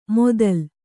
♪ modal